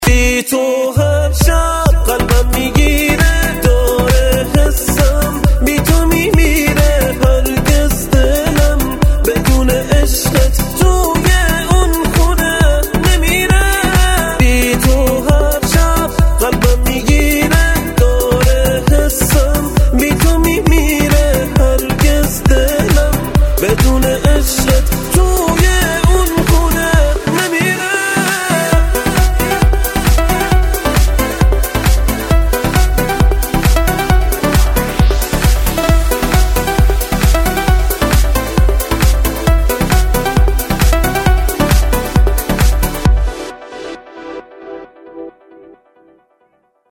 زنگ خور ریتمیک و عاشقانه